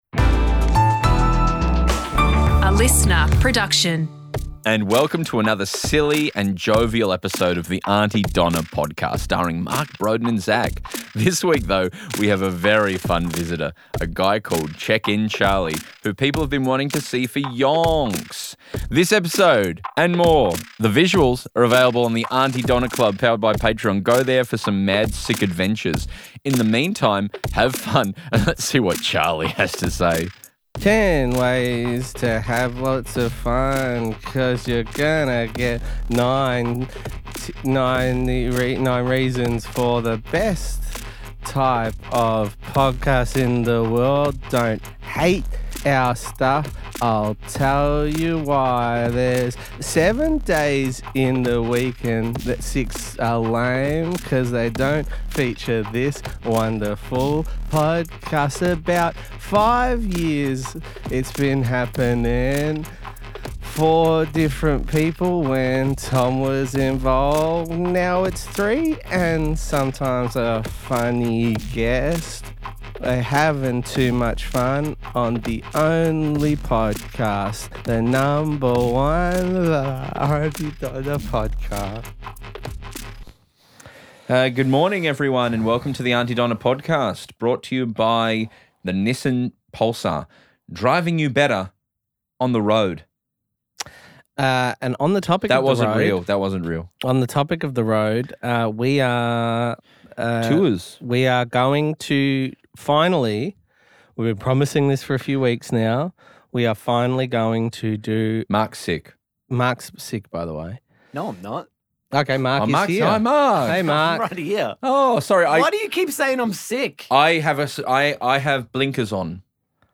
He's just checking in...dropping in... seeing how things are going... touching base... circling back... all in between a few licks on the ol' acoustic.